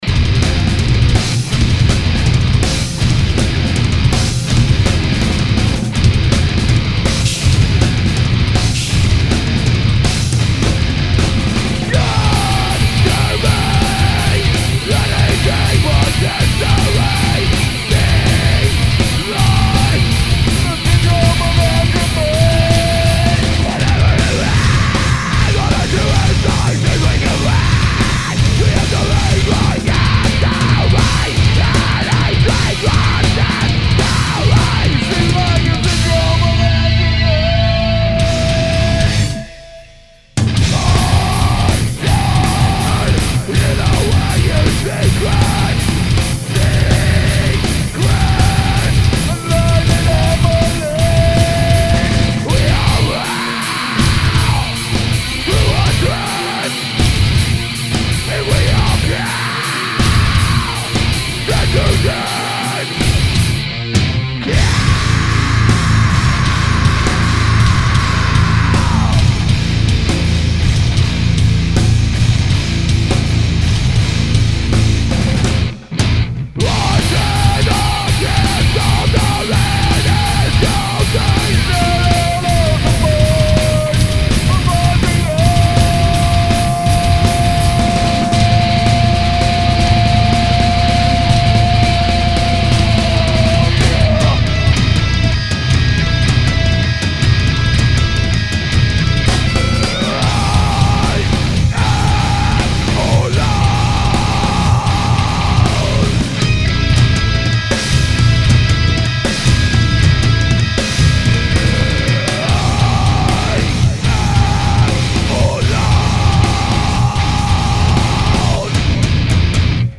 Heavy metal
Thrash/hardcore